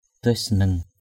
/d̪əɪ’s-sa-nɯŋ/ (đg.) nhận xét. comment.